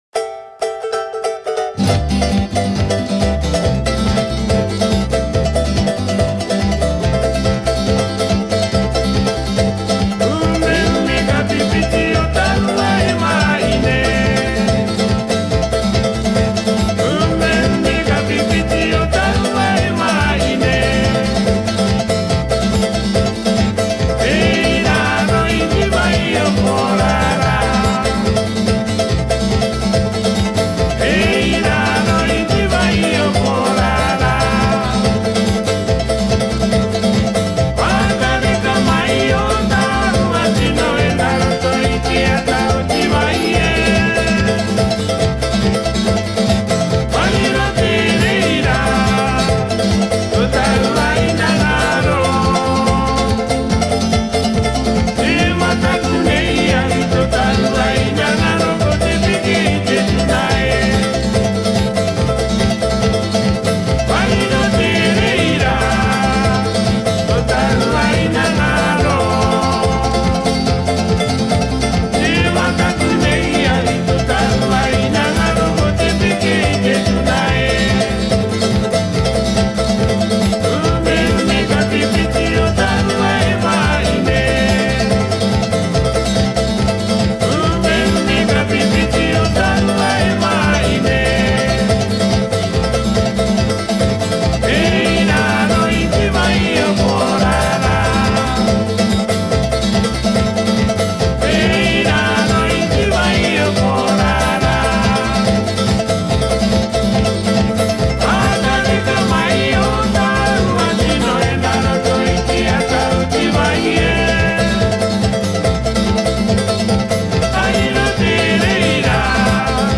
cook-islands-stingband-4.mp3